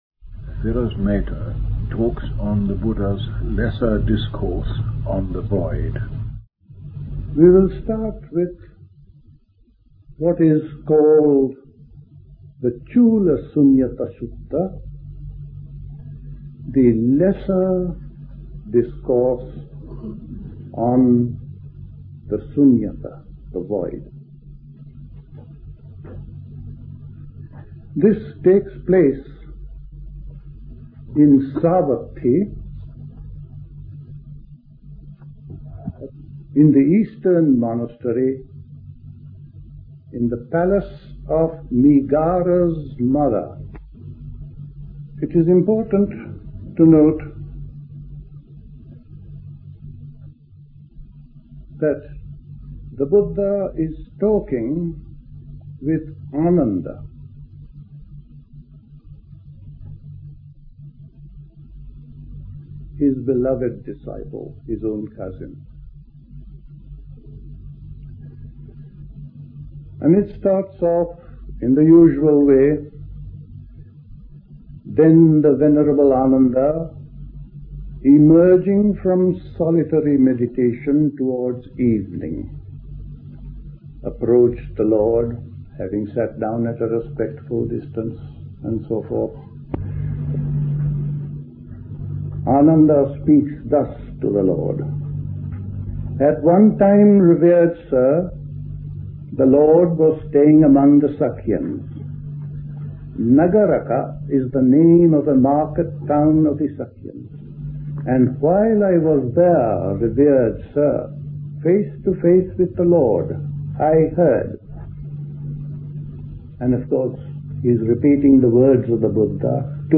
Recorded at the 1975 Buddhist Summer School.